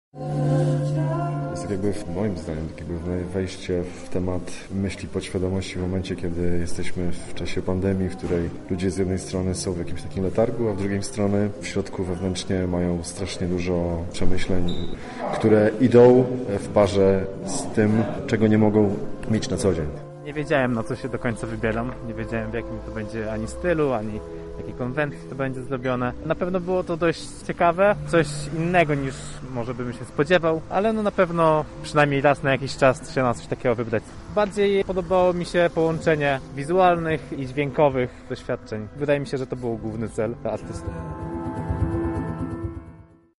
Wrażeniami podzieli się widzowie obecni na wydarzeniu: